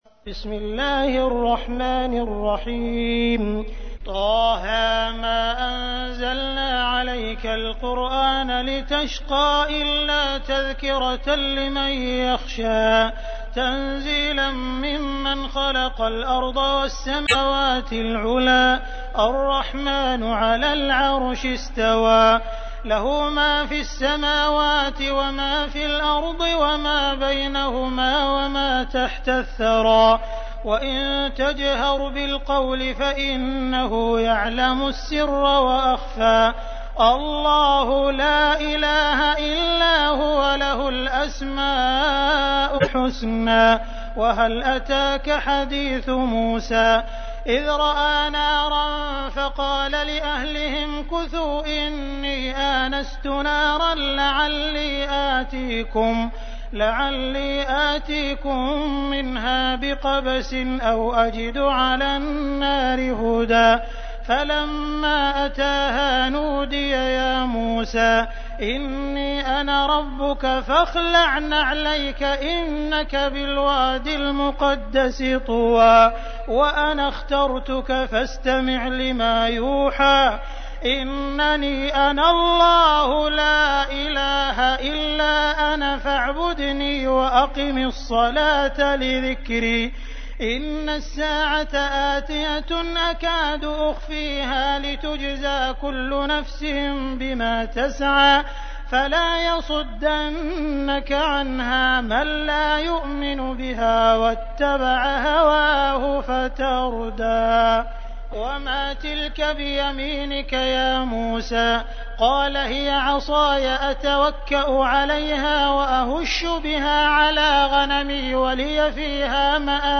تحميل : 20. سورة طه / القارئ عبد الرحمن السديس / القرآن الكريم / موقع يا حسين